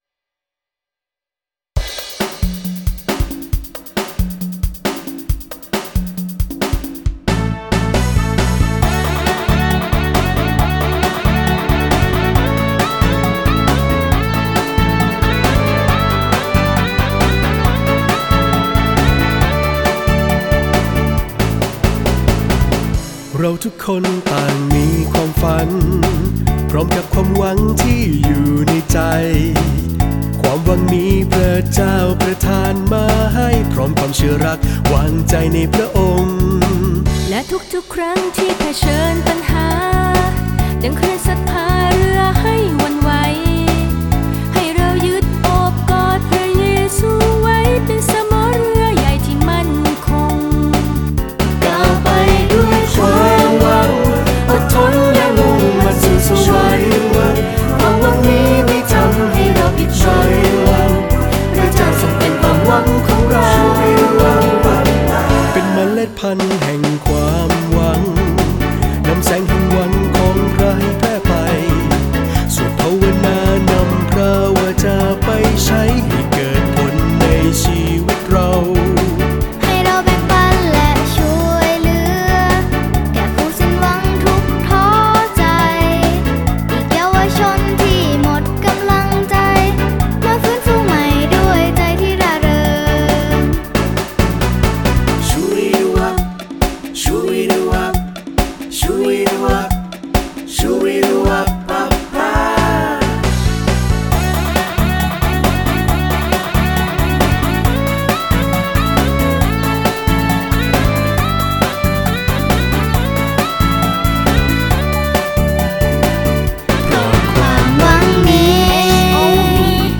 ++ขับร้อง